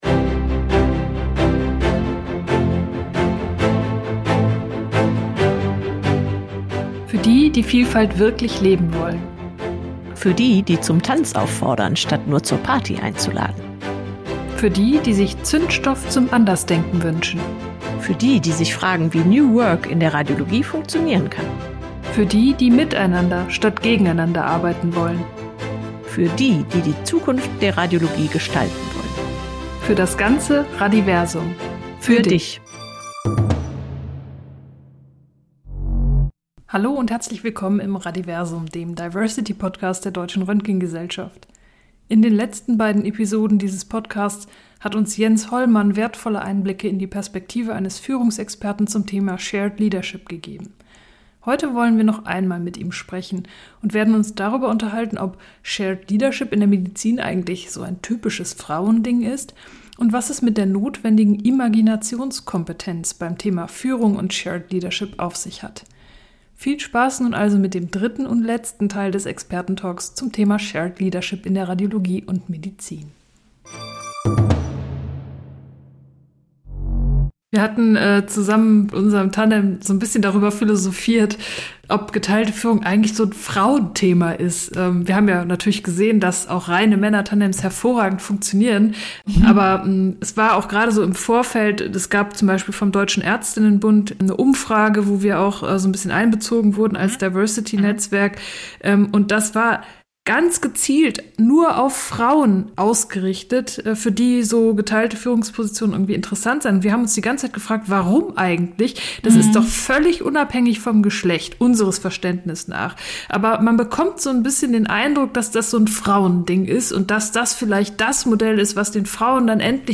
Experten-Talk